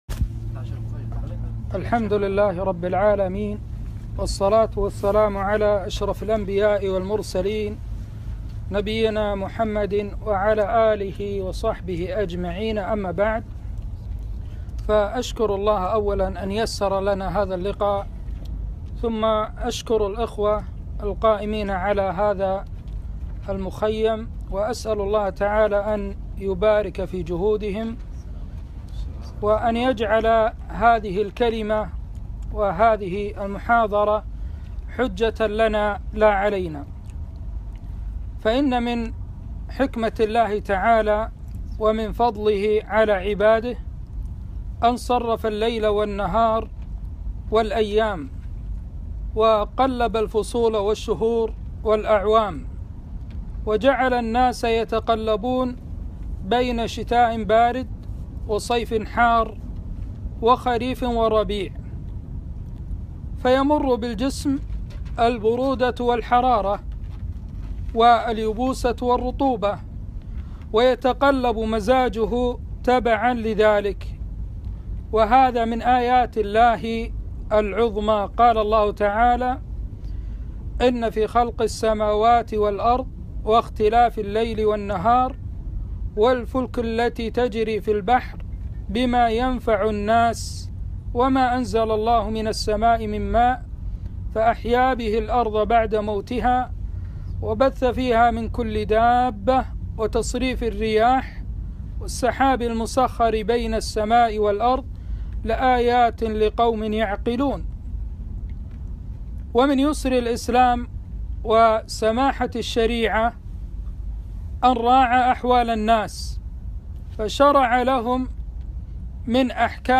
محاضرة - الشتاء أحكام وآداب - دروس الكويت